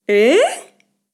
Mujer sorprendida: ¡Ehhh!
exclamación
mujer
sorpresa
Sonidos: Voz humana